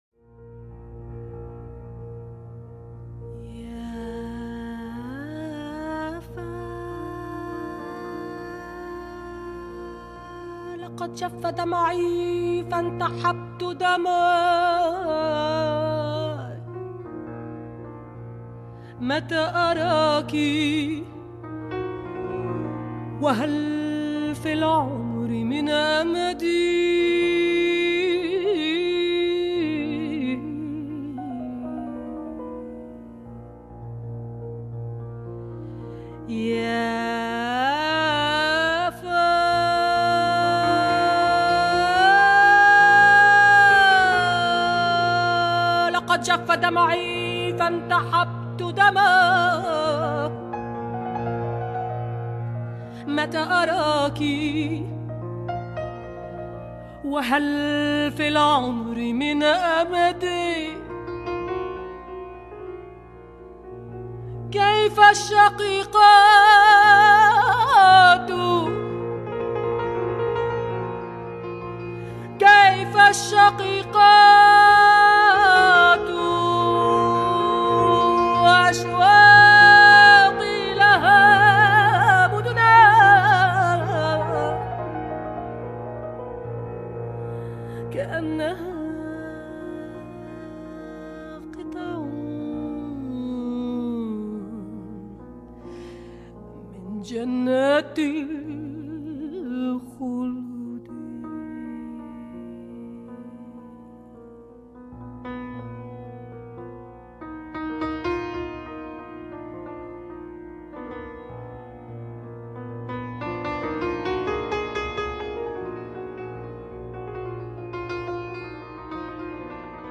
on the piano.’